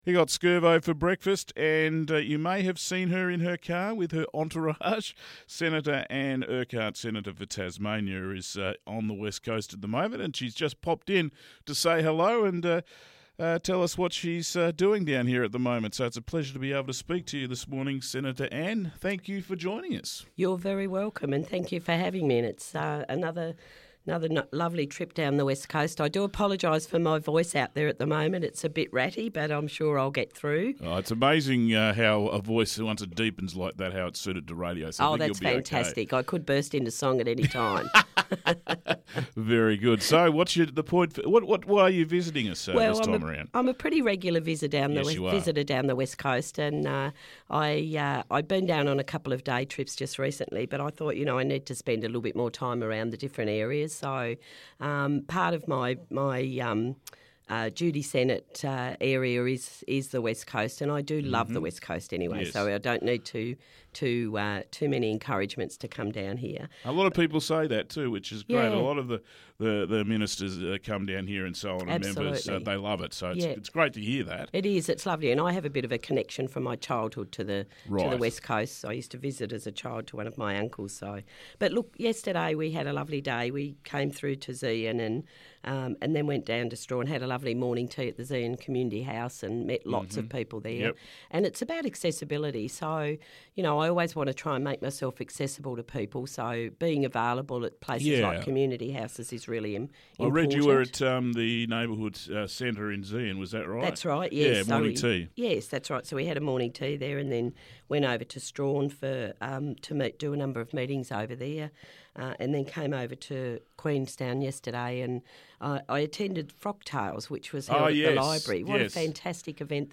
Interview with Senator Anne Urquhart 13th October